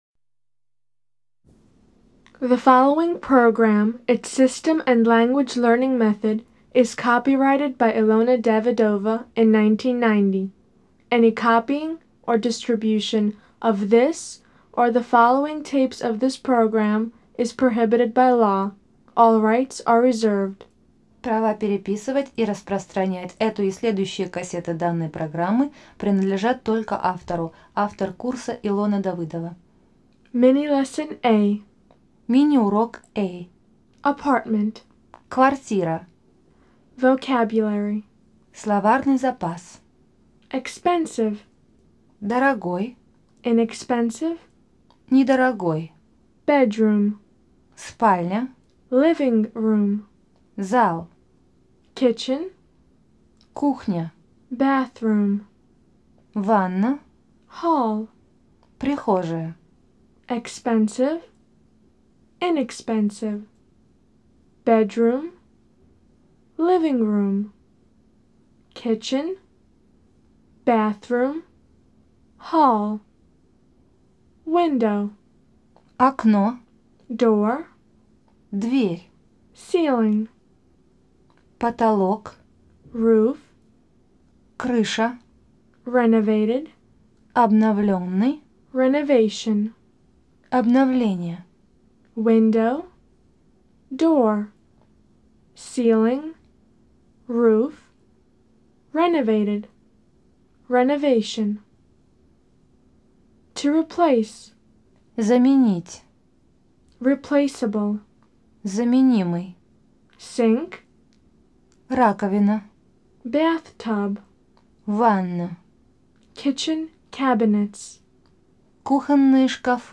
Аудиокнига Разговорно-бытовой английский. Курс 1. Диск 4. Природа. Погода. Квартира.